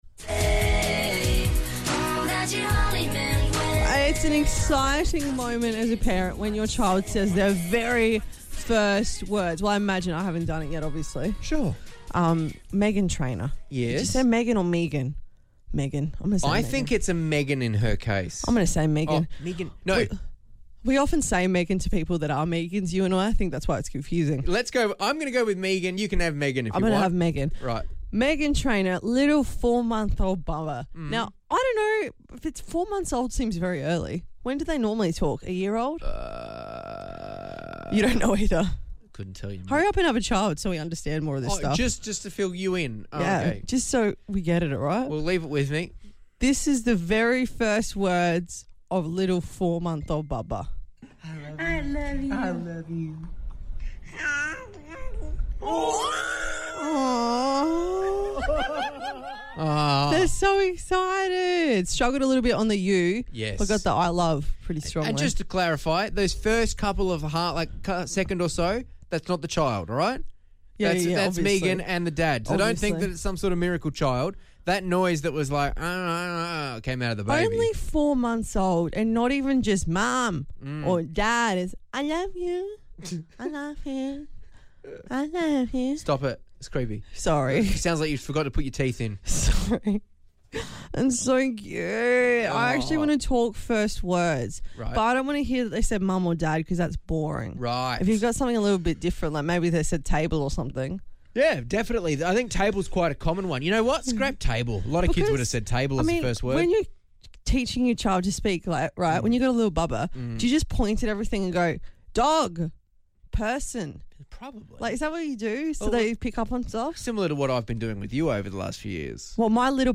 They had a range of callers sharing different stories from naughty words, to dog's names and even a keen fisherman who's first word was "barra!"